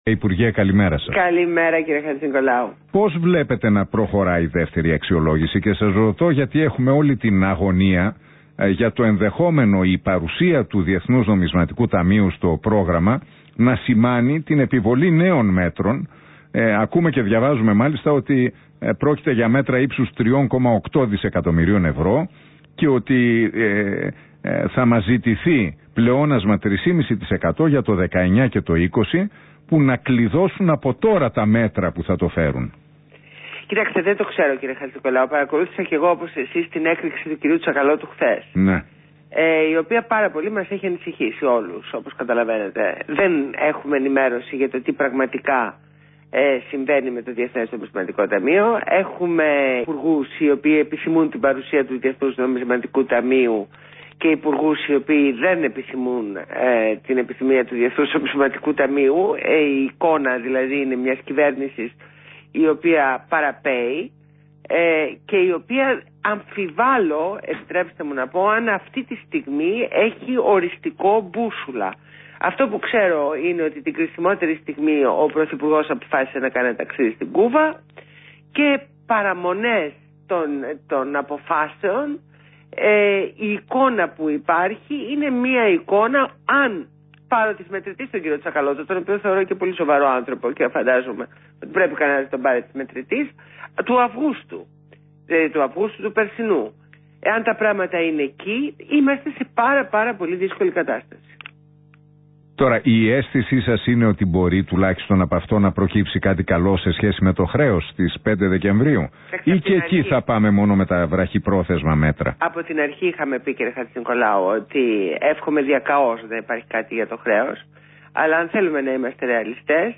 Συνέντευξη στο ραδιόφωνο REALfm στο δημοσιογράφο Ν. Χατζηνικολάου.